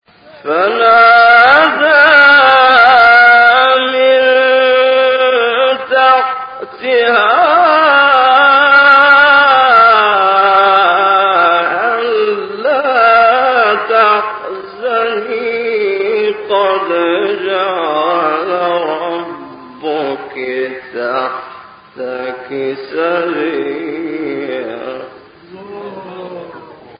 گروه شبکه اجتماعی: نغمات صوتی از قاریان ممتاز مصری ارائه می‌شود.